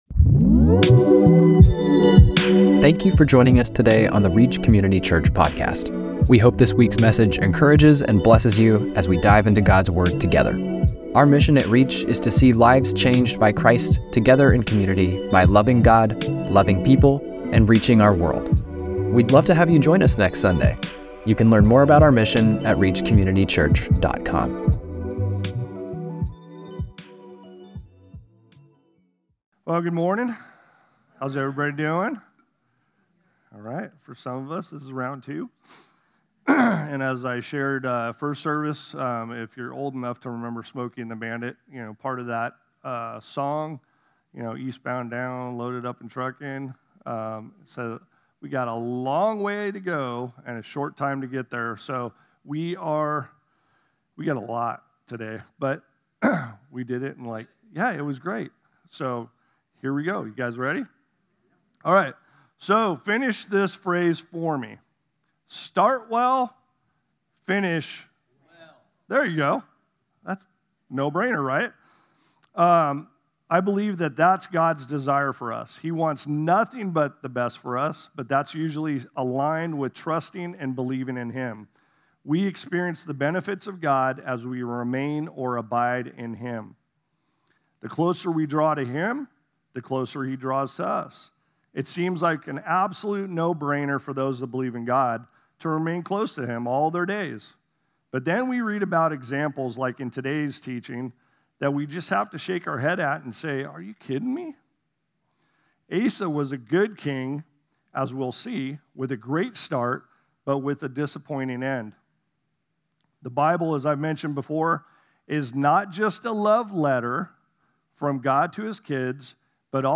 8-3-25-Sermon.mp3